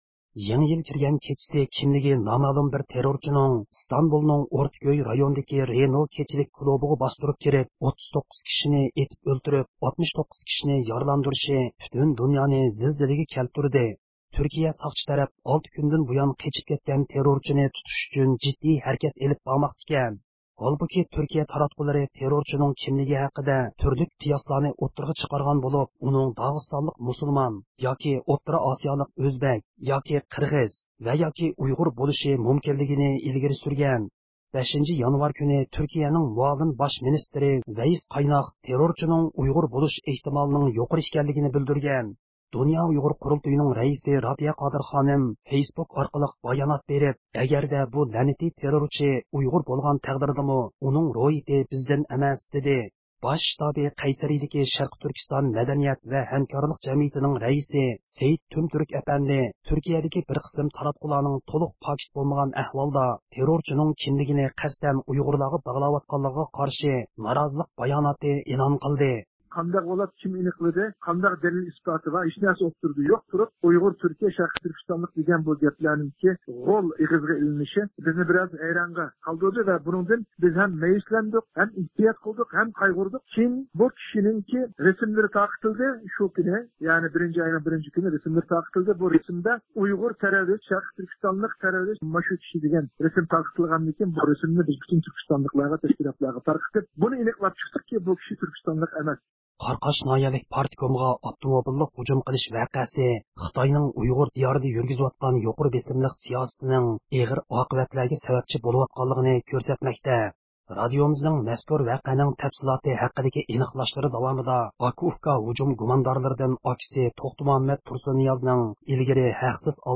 ھەپتىلىك خەۋەرلەر (31-دېكابىردىن 6-يانۋارغىچە) – ئۇيغۇر مىللى ھەركىتى